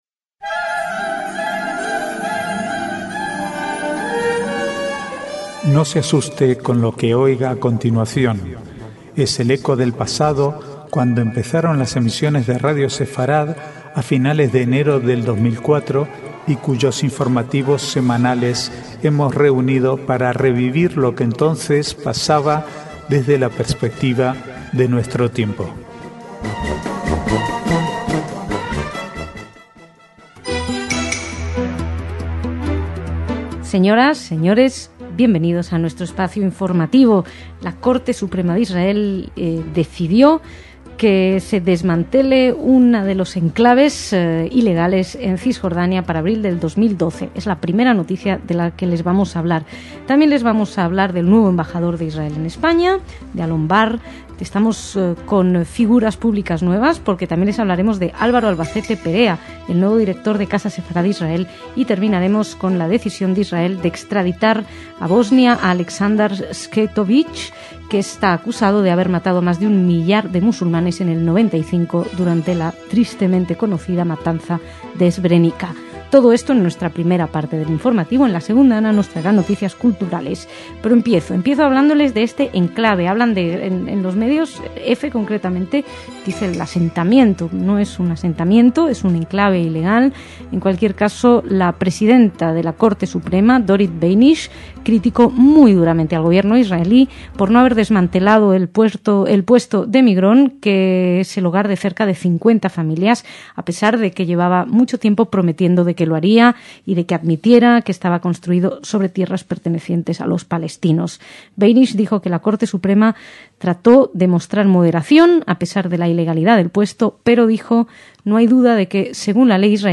Archivo de noticias del 3 al 9/8/2011